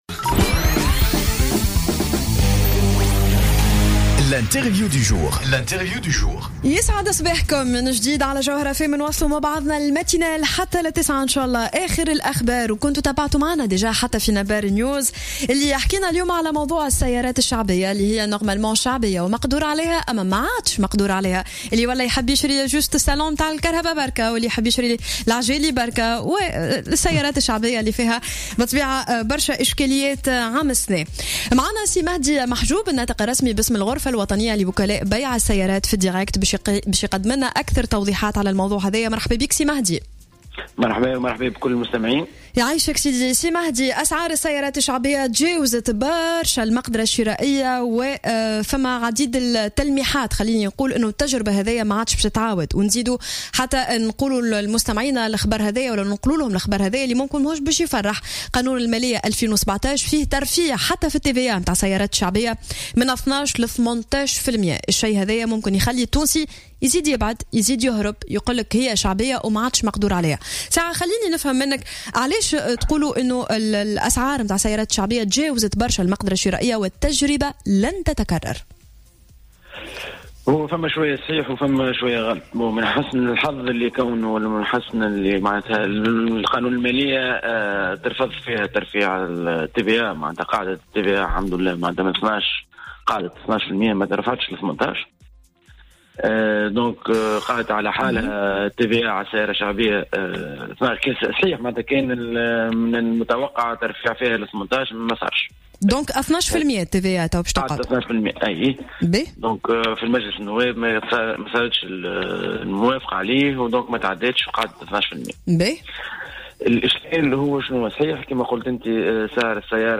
مداخلة له على الجوهرة "اف ام"